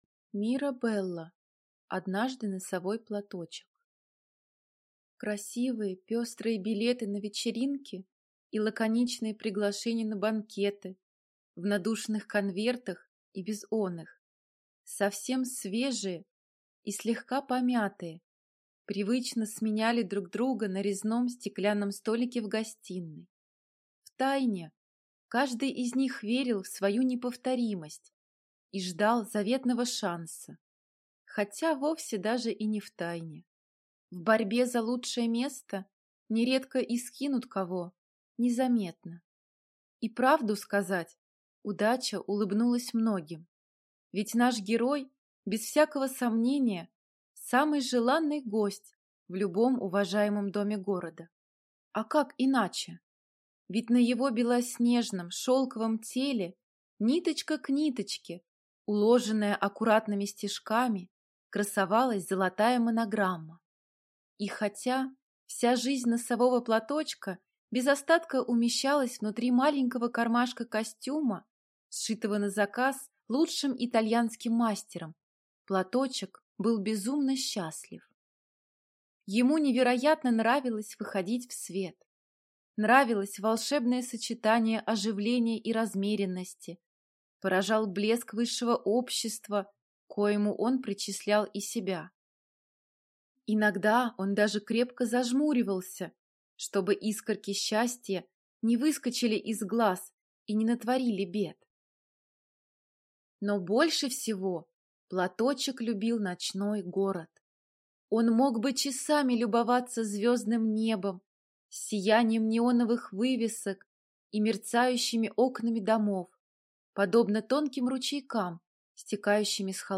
Аудиокнига Однажды носовой платочек…